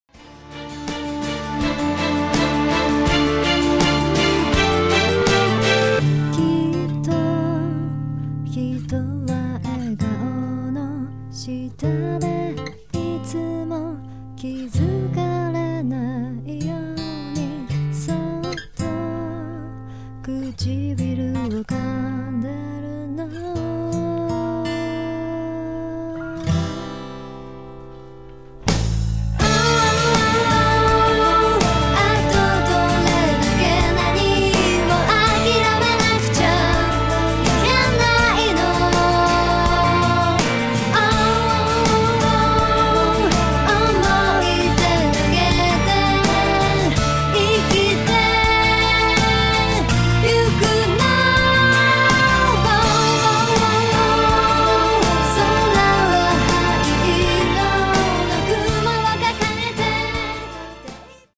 しかも自分達のカラーができあがっていて、どんな曲でもその外にはみだすことがありません。ヴォーカルだけに限らず、ギターやリズムの構築の仕方にも独自性があるのでおもしろいのです。